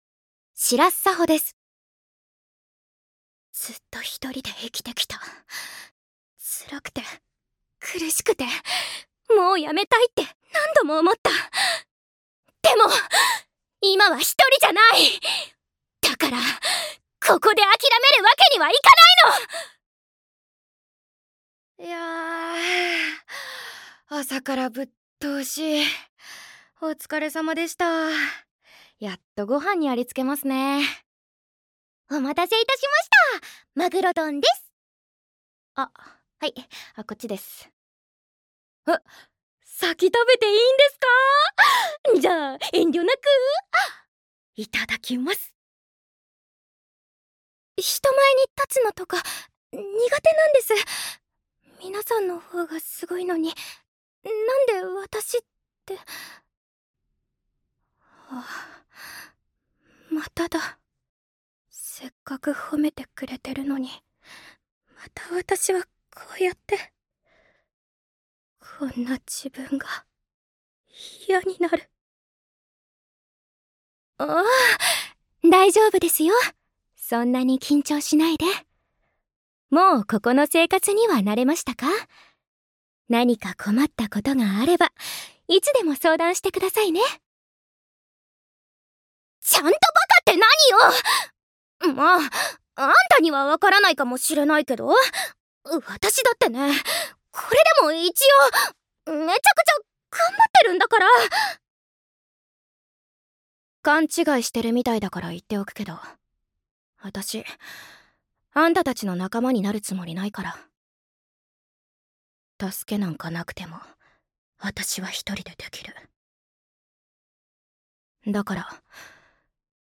サンプルボイス
関西弁